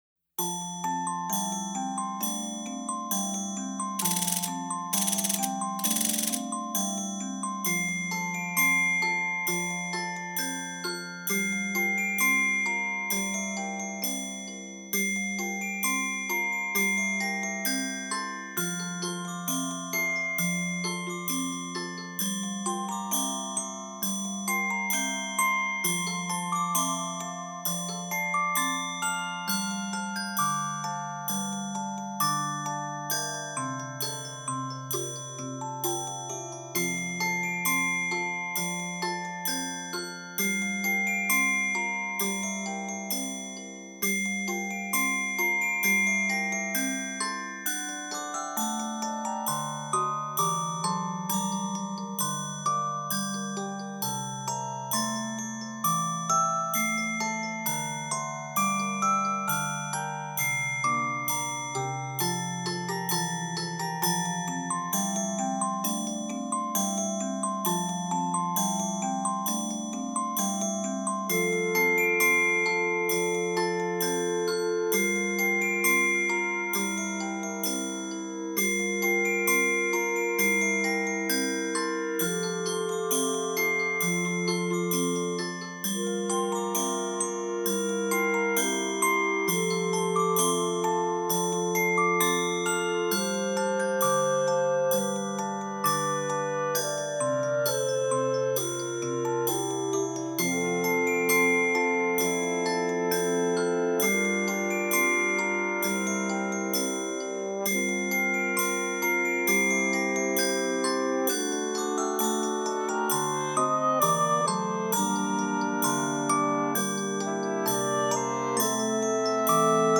Minus Track